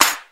• Old School Rap Snare F# Key 17.wav
Royality free snare drum sound tuned to the F# note. Loudest frequency: 4077Hz
old-school-rap-snare-f-sharp-key-17-XwG.wav